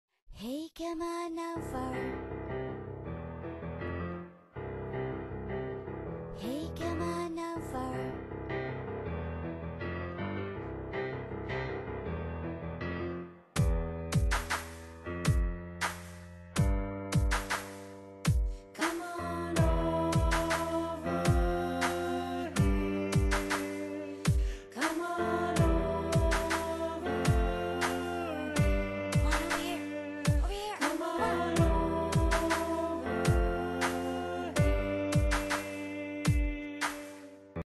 Quibble (quintet)